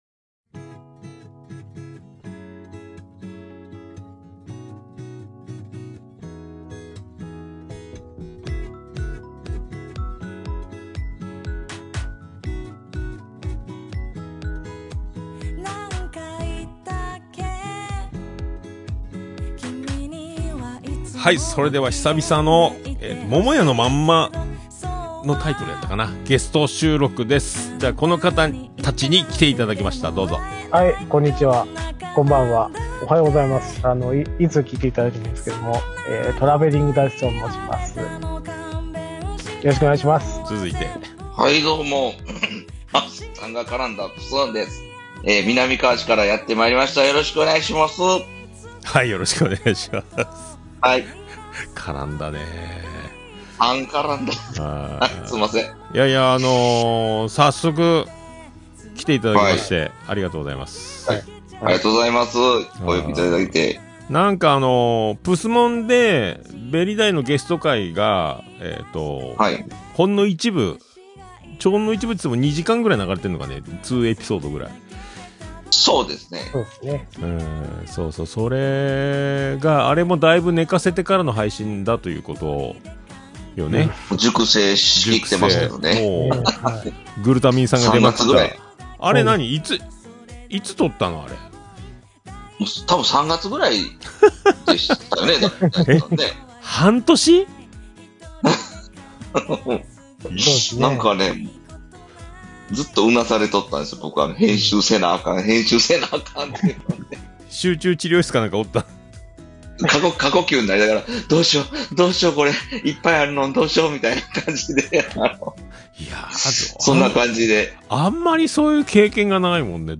今回はオルネポともも屋の話題で盛り上がっていたお二人を速攻招いて収録しました。